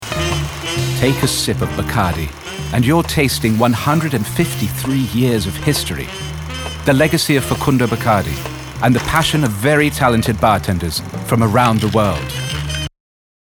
sophisticated, smooth, concise